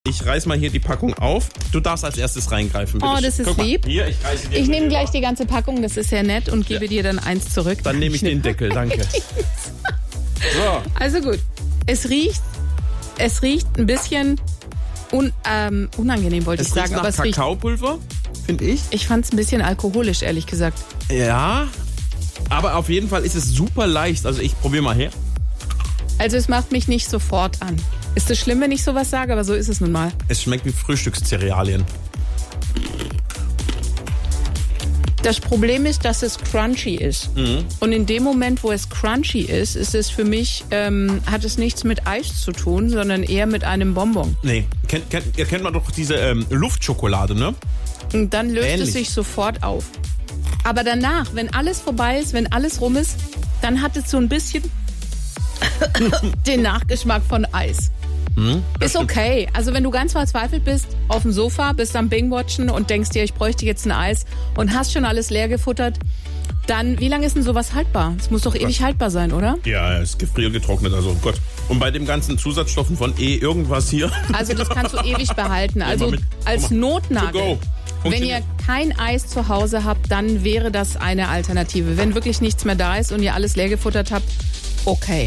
Ihre niederschmetternde Bewertung im Radio.